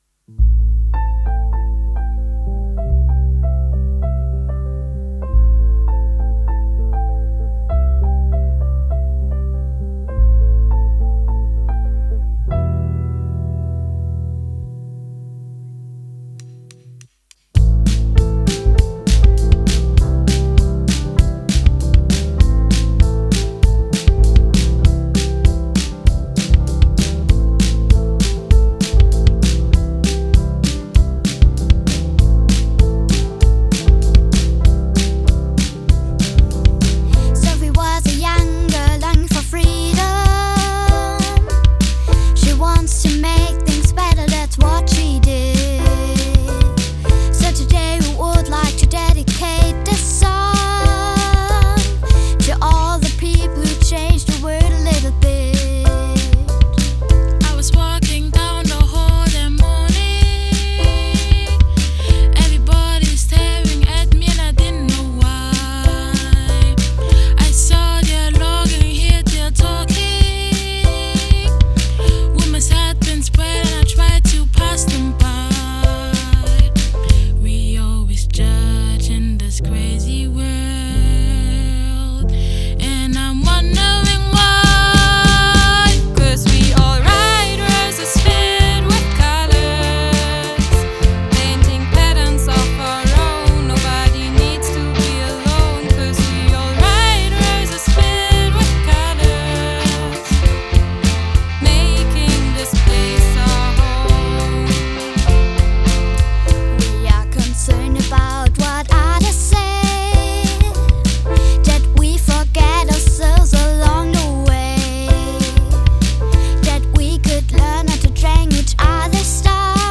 Gemeinsame Aufnahme von Schulband und Schulgemeinschaft im Rahmen unseres Mottojahres „Miteinander“:
Bei der Schulveranstaltung „Musik trifft Kunst“ hat die Schulgemeinschaft den Refrain zusätzlich als Gruppe eingesungen. Dieser ist nun am Ende des Songs zu hören.